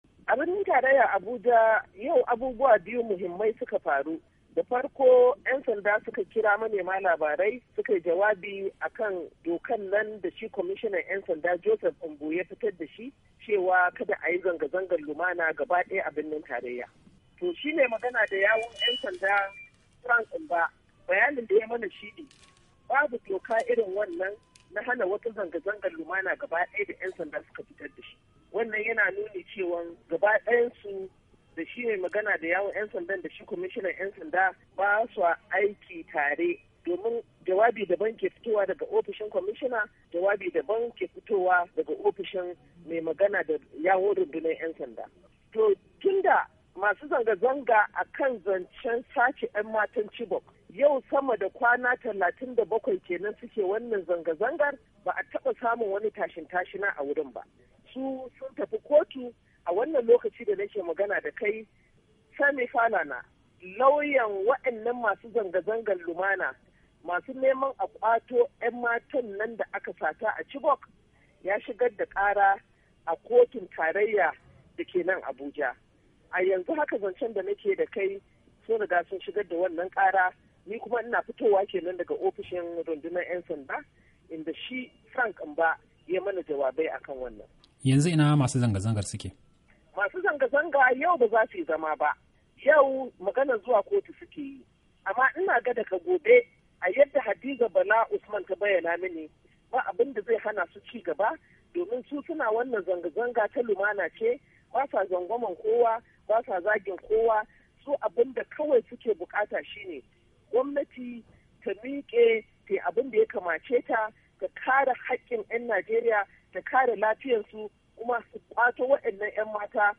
Mai Magana da yawun ‘yan sanda Frank Mba, ne ya furta haka a lokacin da yakewa 'yan jaridu bayani a Abuja.